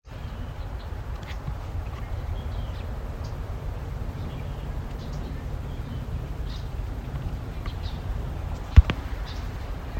MacGillivray's Warbler